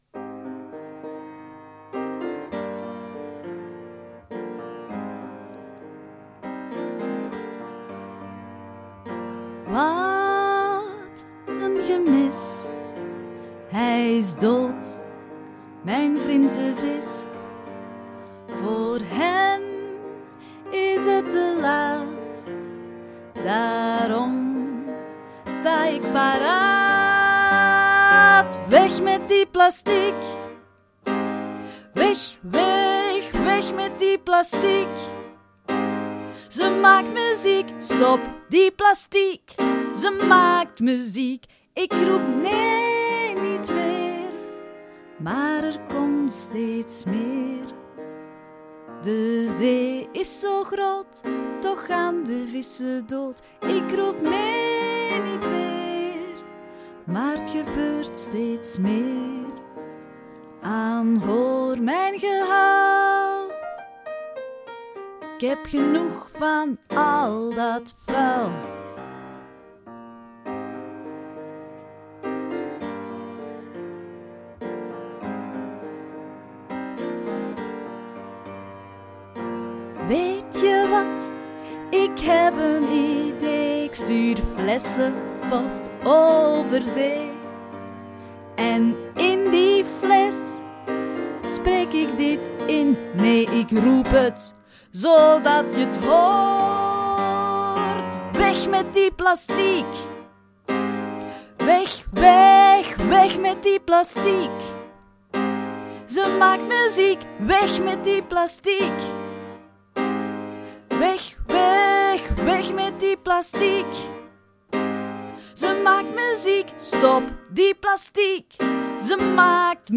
piroos_vis_zang.mp3